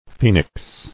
[phe·nix]